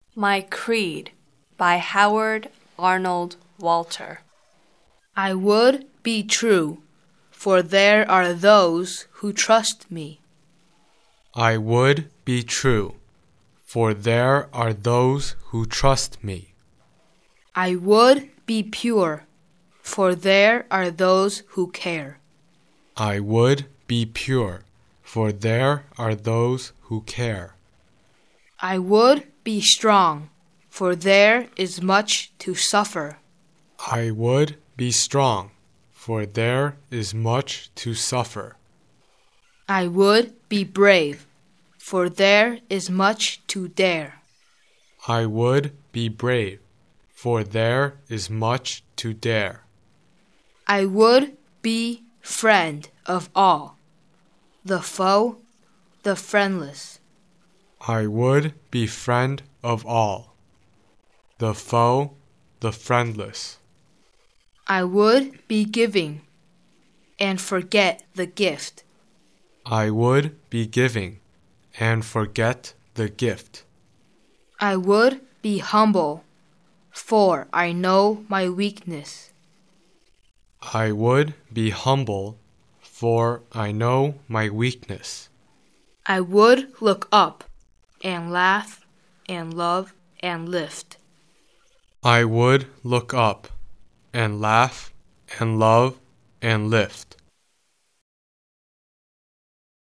English beautiful reciting poems http